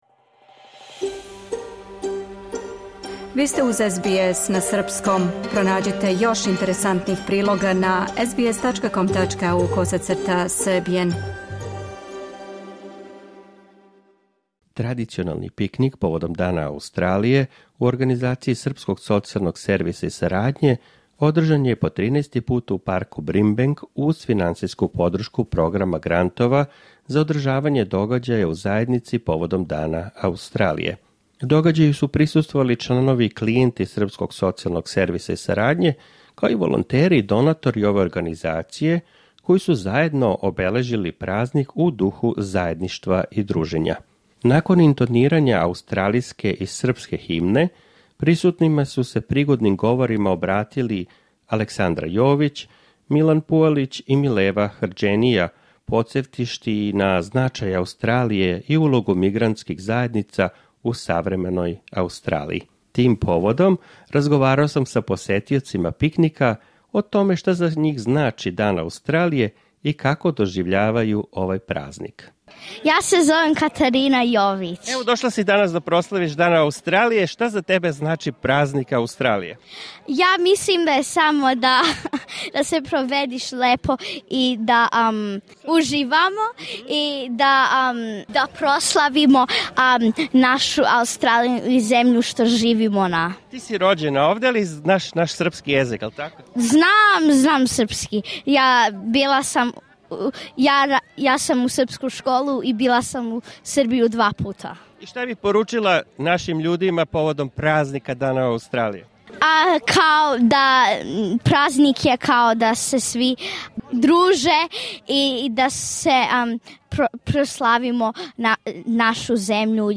Са пикника у Бримбенку.